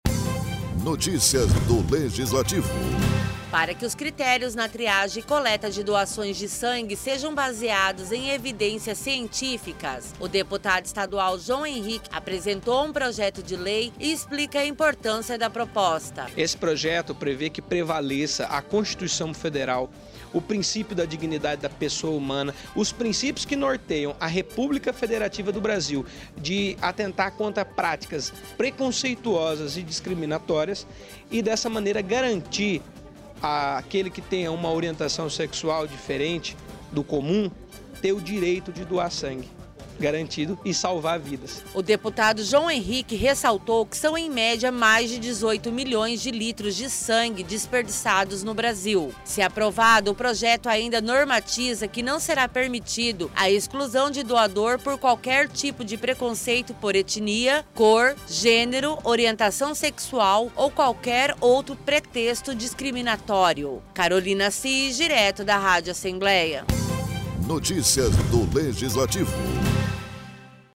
Durante a sessão plenária desta terça-feira, o deputado estadual João Henrique, do PL criticou as regras discriminatórias para doadores de sangue e propôs mudanças, para que os critérios na triagem e coleta de doações de sangue e derivados sejam baseados em evidências científicas.